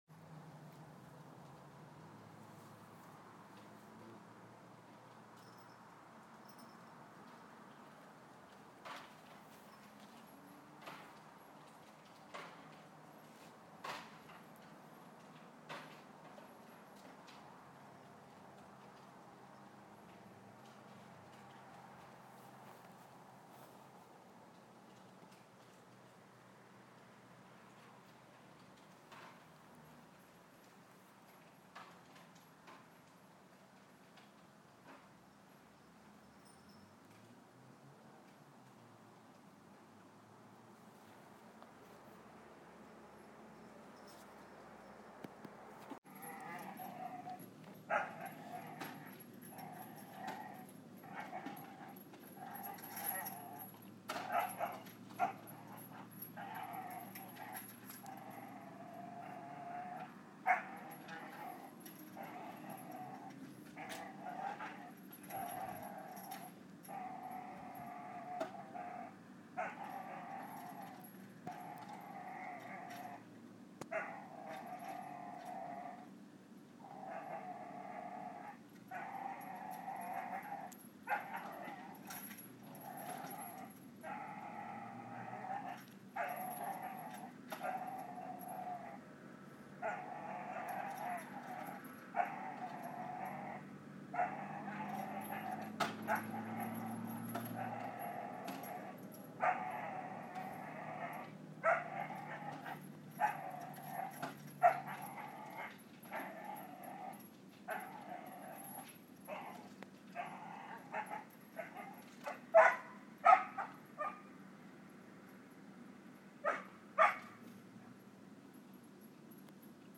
dog vs child's swingset, swingset gets the worst of it!
the neighbors dog attacking there swingset, he really gets cranky with it at the end. I recorded this from a distance. but it was night, so very quiet, and recorded quite clearly.